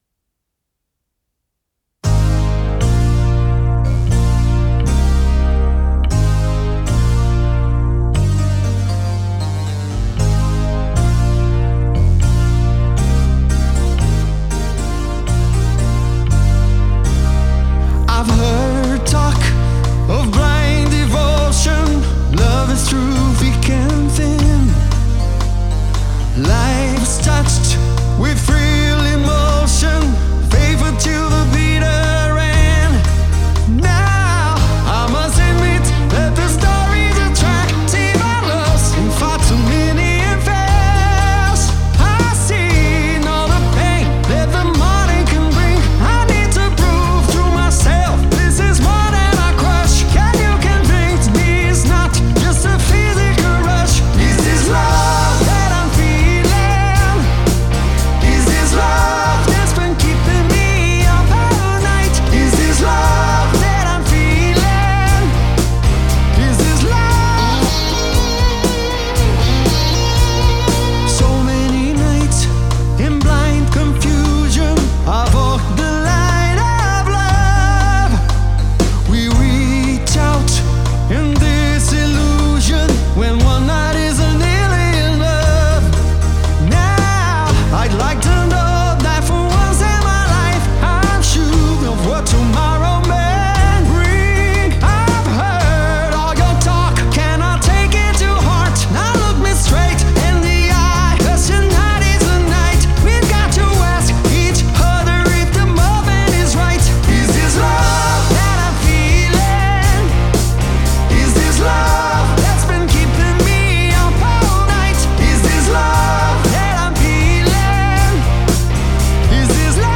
Das Ende ist nicht so richtig schön (in den Originalspuren).